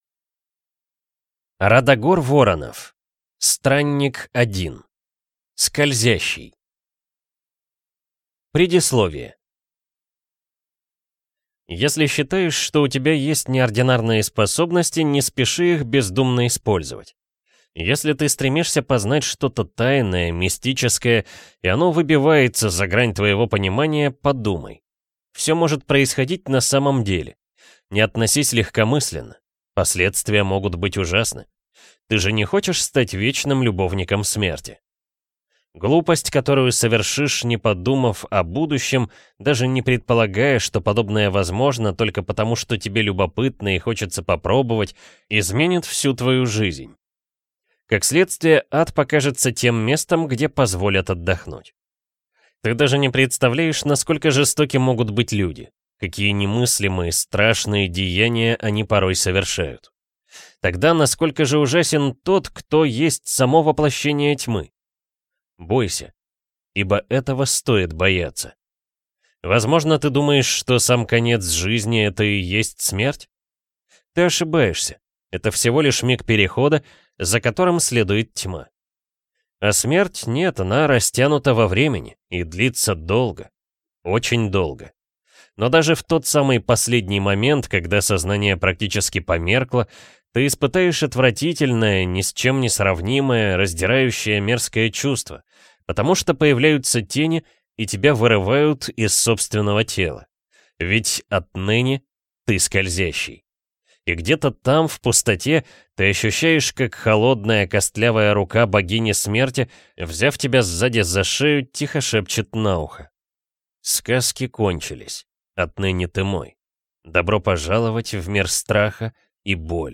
Аудиокнига Странник-1. «Скользящий» | Библиотека аудиокниг